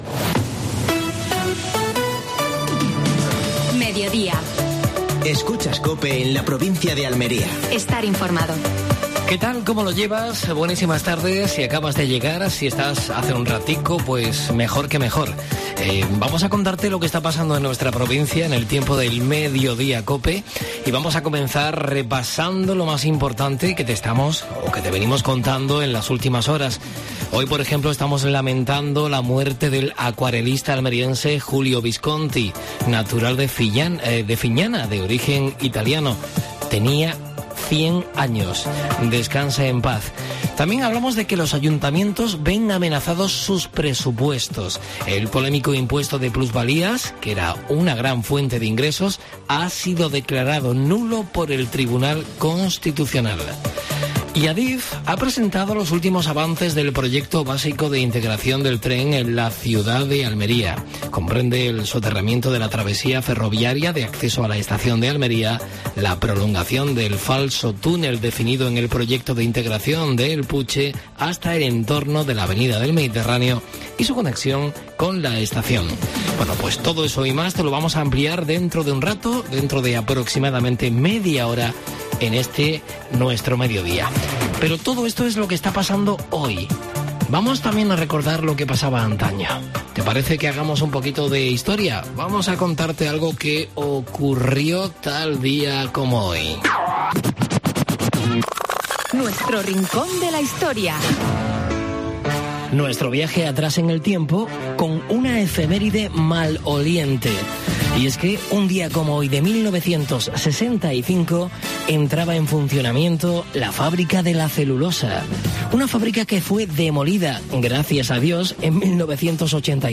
Última hora deportiva.